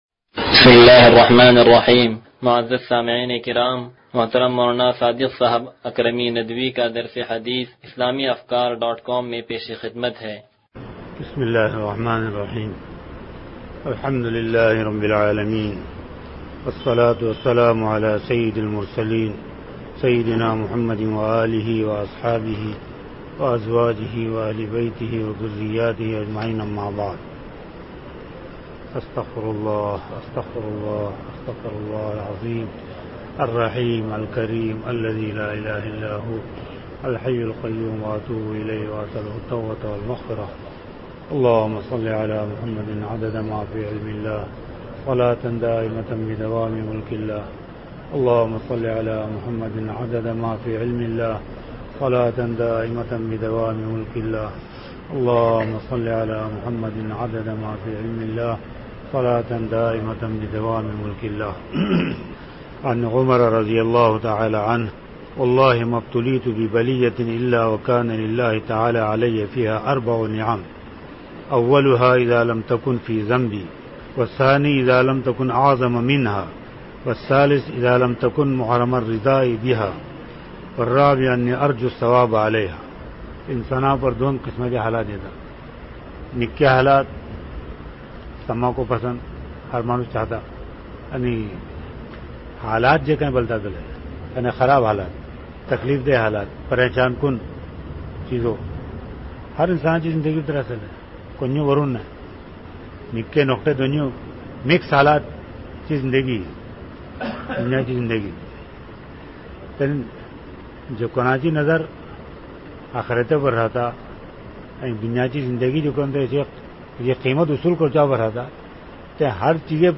درس حدیث نمبر 0081